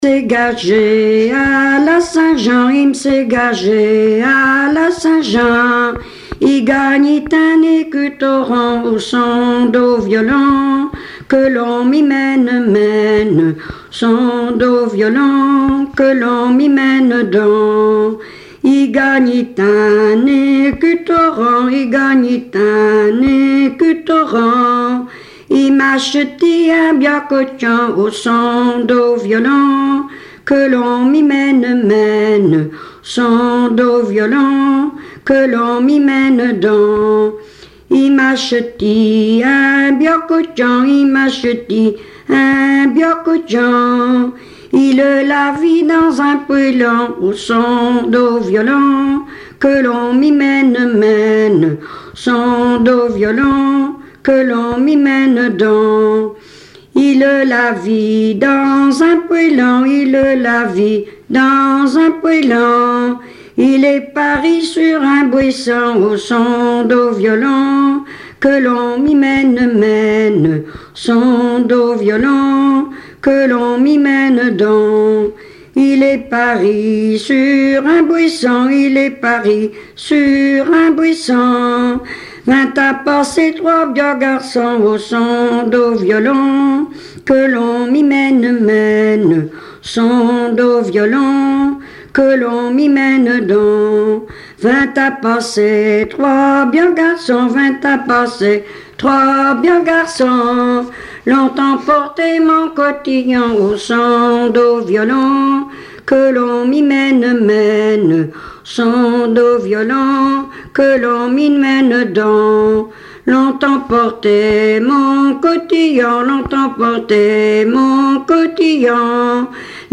Langue Patois local
Genre laisse
Chansons traditionnelles et populaires
Pièce musicale inédite